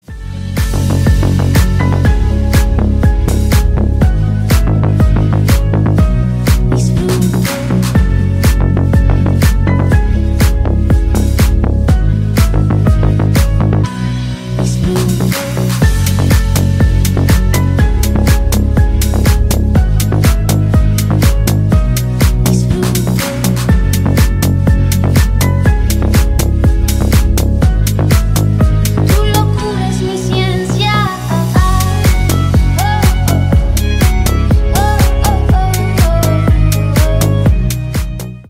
Ремикс # Танцевальные
латинские # клубные